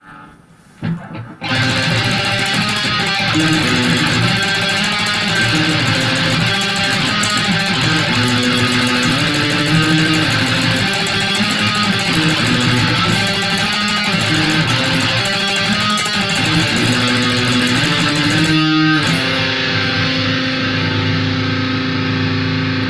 Я raw блэкуху(потому что аудиоинтерфейса нету) думаю в соло записать, потому что у меня тремоло-пикинг хорошо получается, например вот: